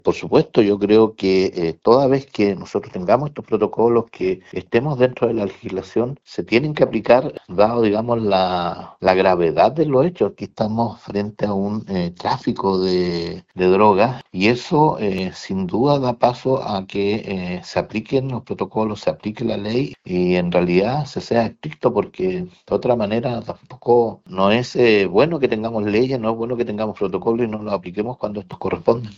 Desde el municipio, el alcalde Ricardo Fuentes, dijo que es necesario que se apliquen los protocolos correspondientes, incluso la Ley de Aula Segura.